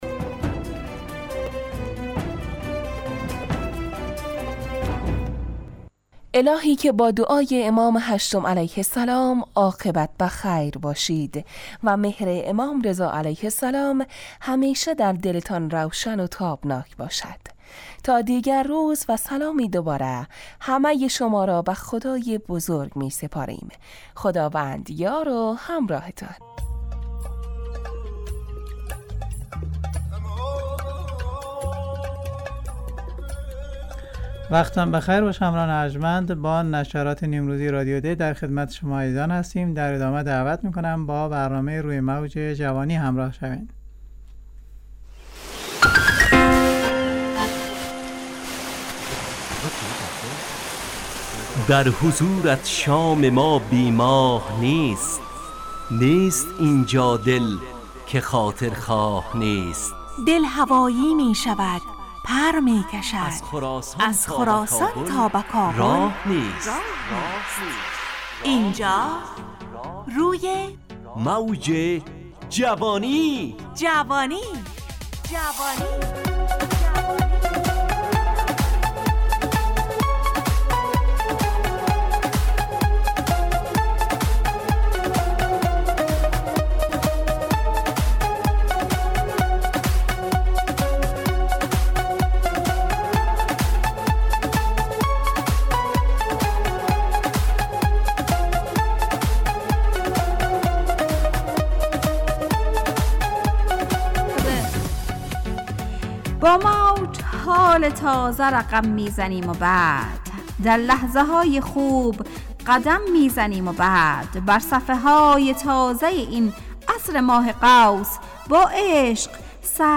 روی موج جوانی، برنامه شادو عصرانه رادیودری. از شنبه تا پنجشنبه ازساعت 17 الی 17:55 به وقت افغانستان، طرح موضوعات روز، وآگاهی دهی برای جوانان، و.....بخشهای روزانه جوان پسند. همراه با ترانه و موسیقی مدت برنامه 55 دقیقه .